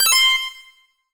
Universal UI SFX / Basic Menu Navigation
Menu_Navigation02_Close.wav